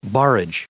830_barrage.ogg